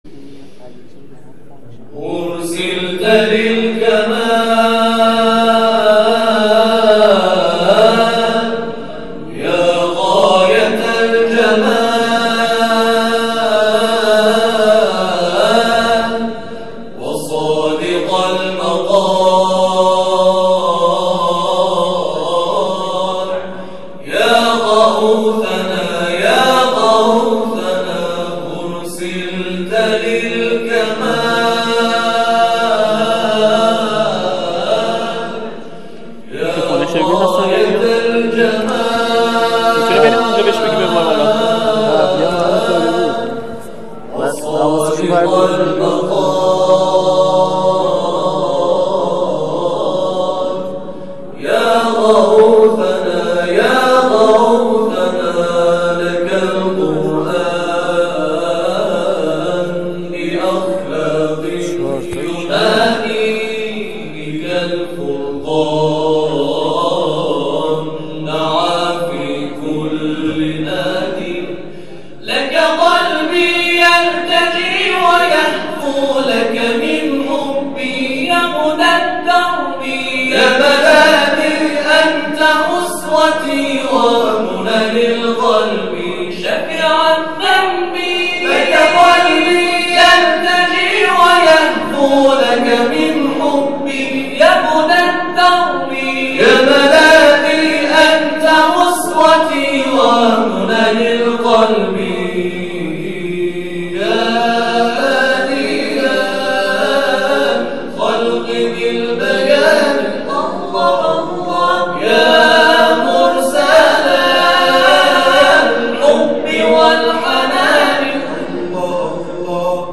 گروه تواشیح ملکوت استان اصفهان
در ادامه اجرای گروه ملکوت در مرحله استانی چهل و سومین دوره مسابقات سراسری قرآن کریم بارگذاری شده است.